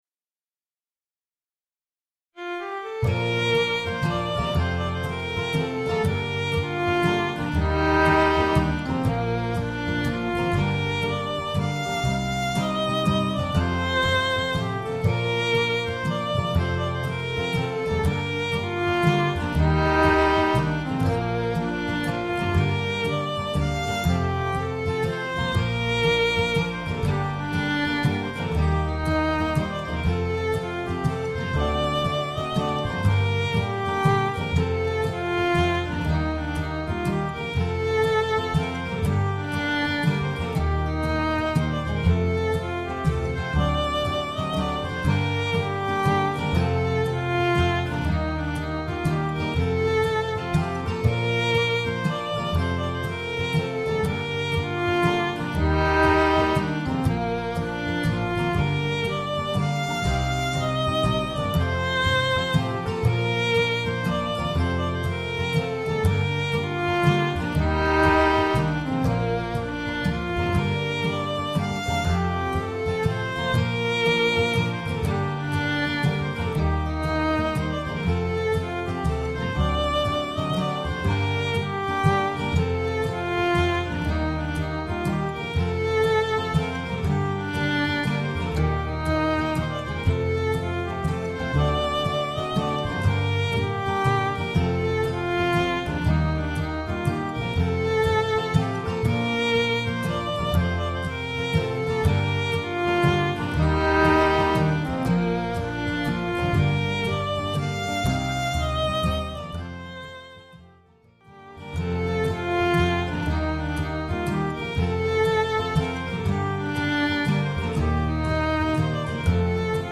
A new waltz!
This my first quarantine project – putting together a recording entirely “in the box”, using Finale and Band-In-A-Box. No live musicians were harmed (or used) in the making of this recording!